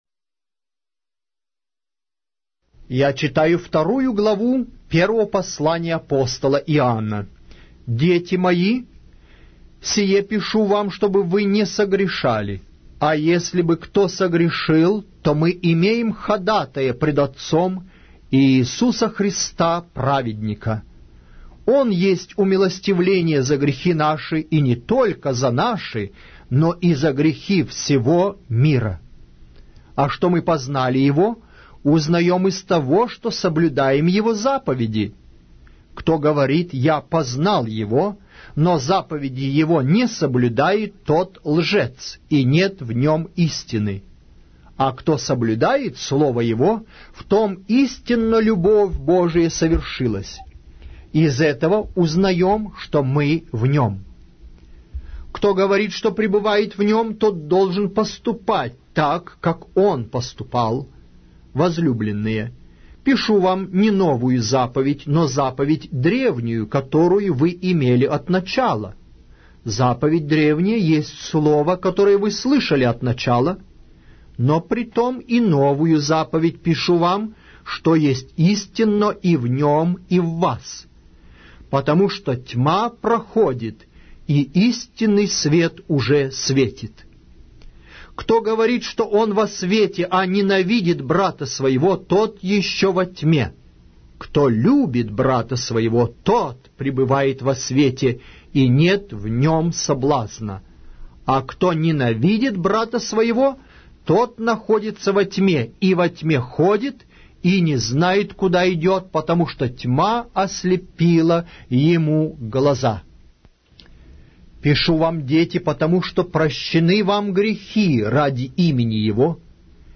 Аудиокнига: 1-е послание Апостола Иоанна